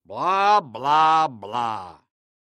Звуки передразнивания
Преподаватель во время приема экзамена